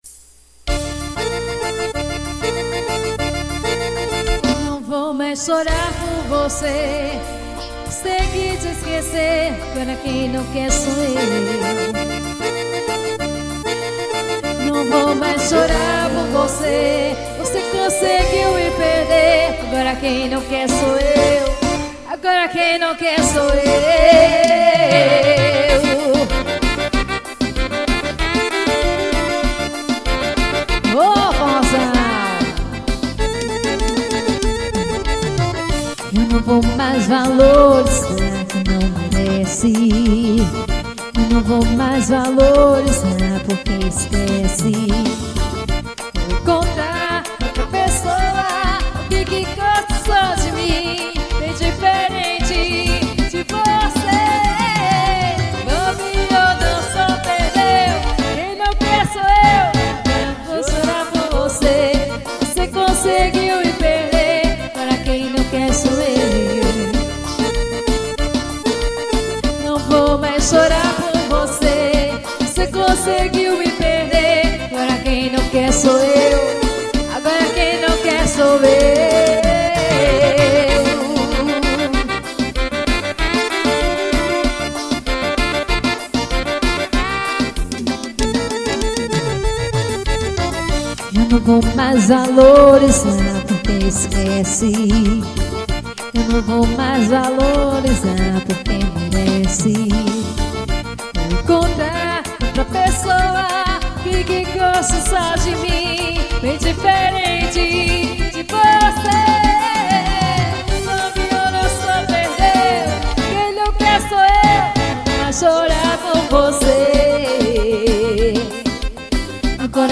cd ao vivo.